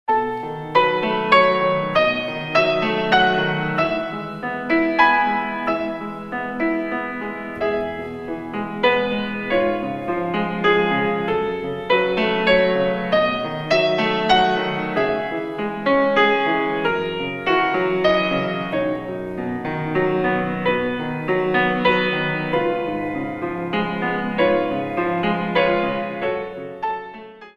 In 3